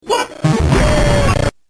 what the boom Meme Sound Effect
This sound is perfect for adding humor, surprise, or dramatic timing to your content.